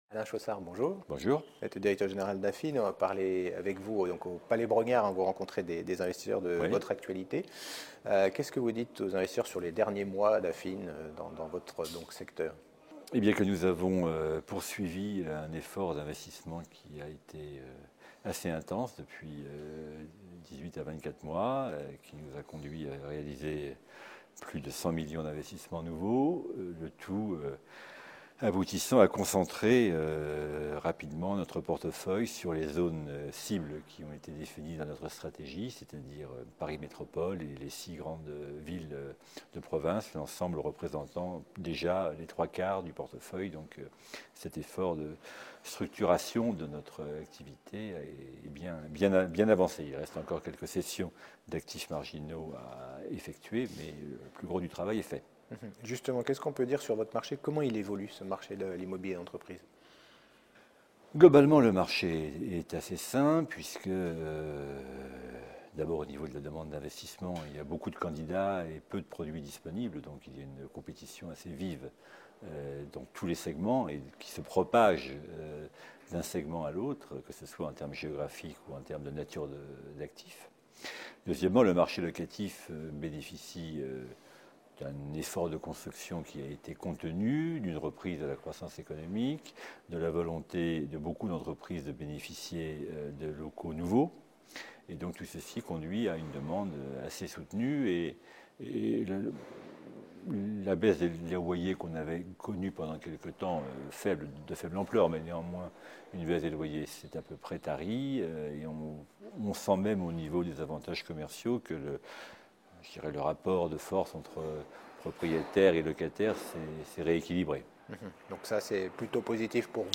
Lors de l’European Large & Midcap Event 2017 organisé par CF&B Communication à Paris, la Web TV partenaire a rencontré de nombreux dirigeants